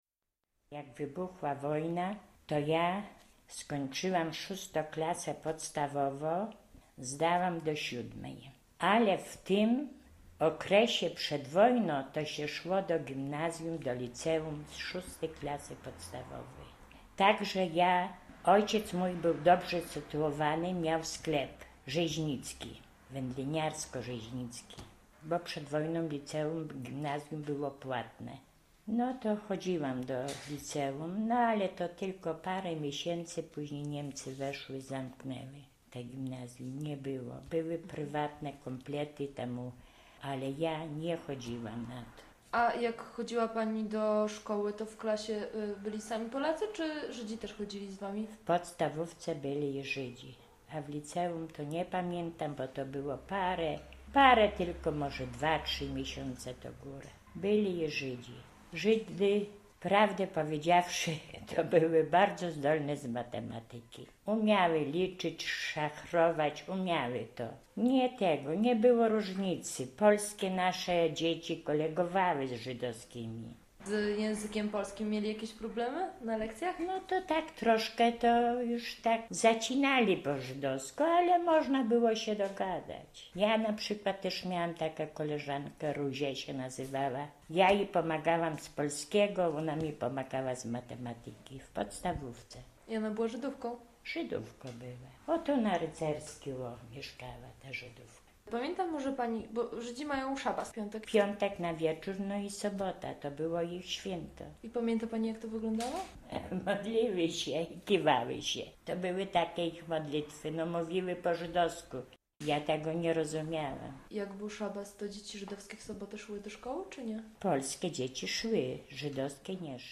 Historia mówiona: